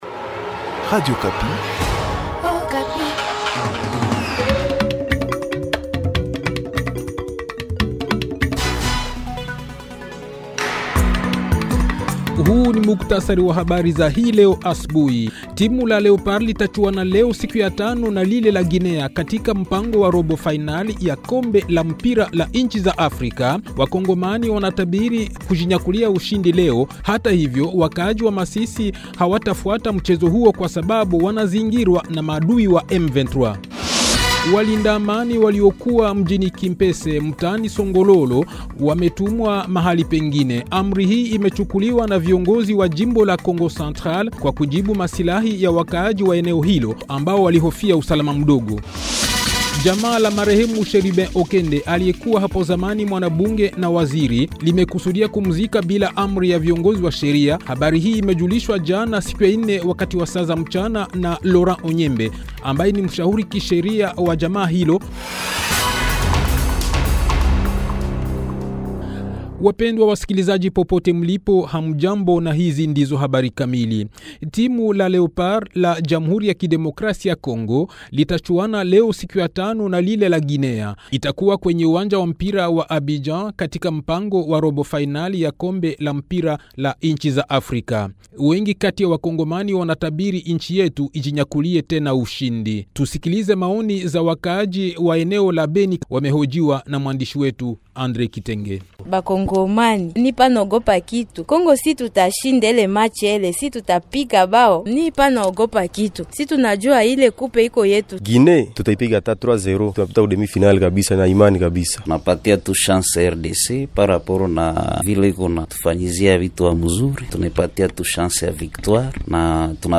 Le journal Swahili de 5 h, 2 fevrier 20274